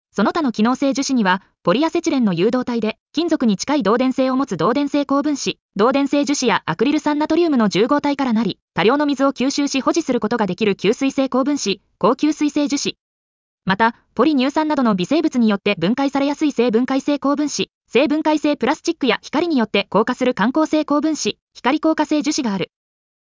• 耳たこ音読では音声ファイルを再生して要点を音読します。
ナレーション 音読さん